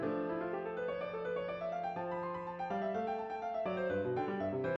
例えば今回のモーツアルトソナタでは、典型的でかつ軽快なスケールが連続する箇所が幾度となく連続します。
右手の細かな音の連続、どの音も均一で美しく淡々と高低音間を遷移していますよね。